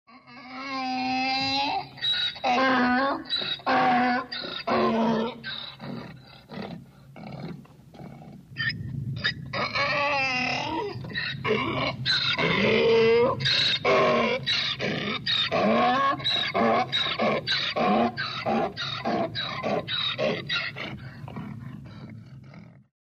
Звуки осла